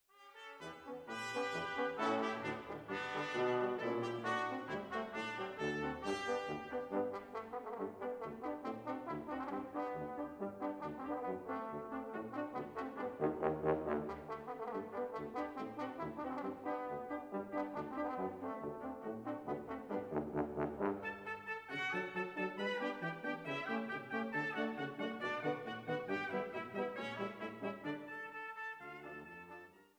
Große Sinfonik für fünf Bläser